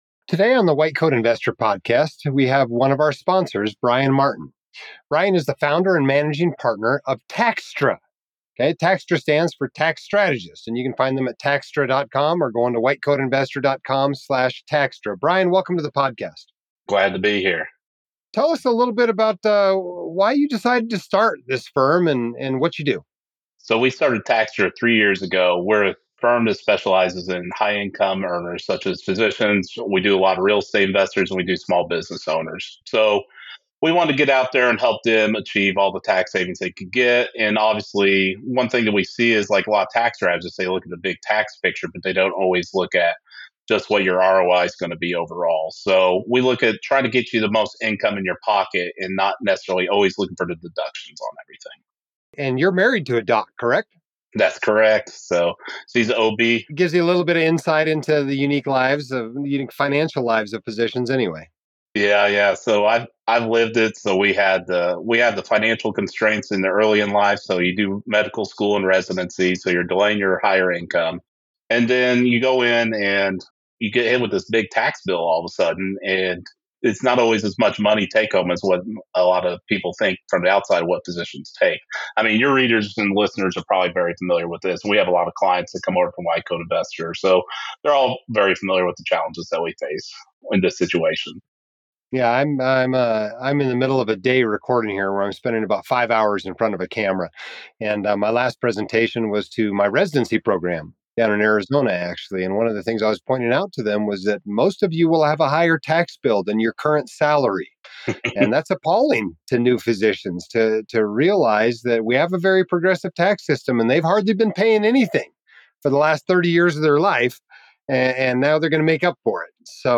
Taxstra-Podcast-Interview-2026.mp3